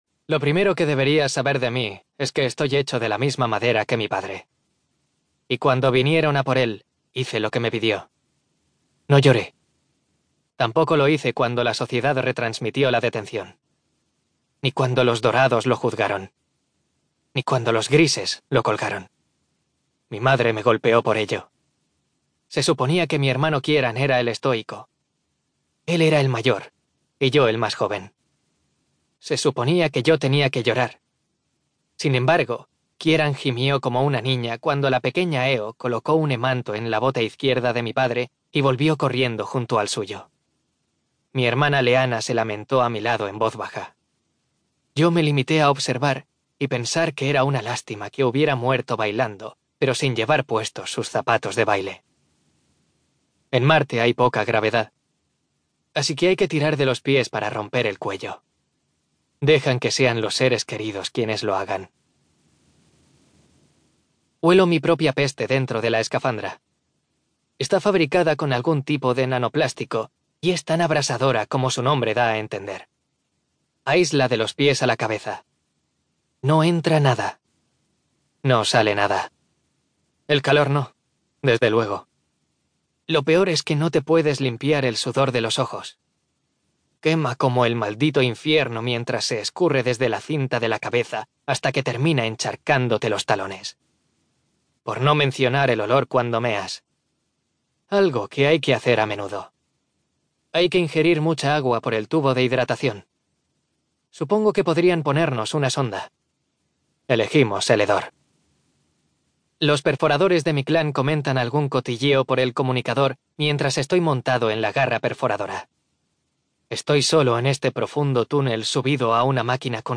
TIPO: Audiolibro CLIENTE: Audible Inc. ESTUDIO: Eclair Barcelona